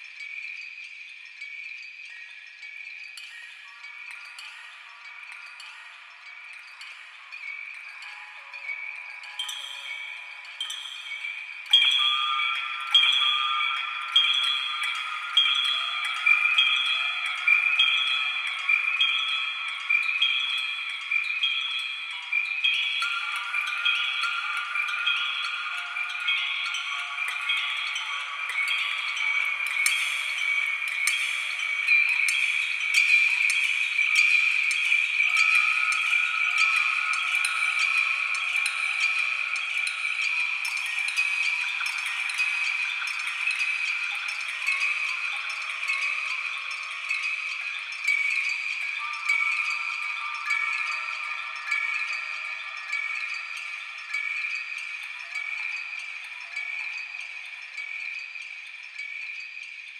cave chimes.ogg